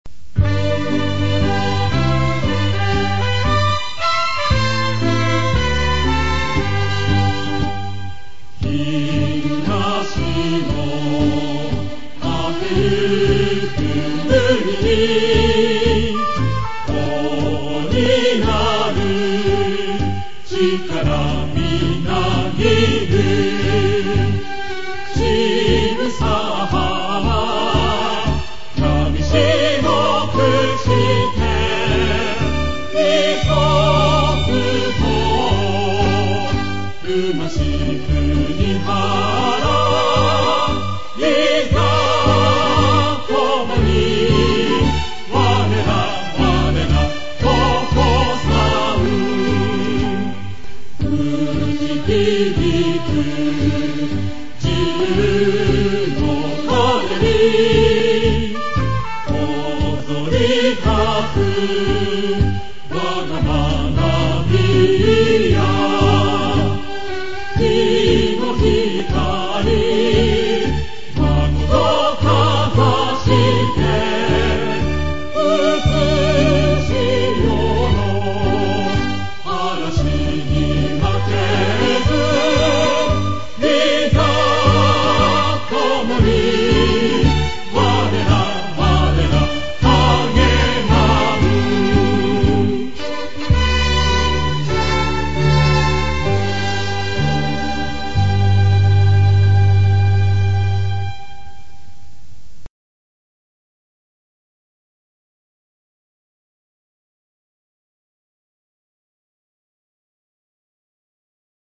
校歌1&4.mp3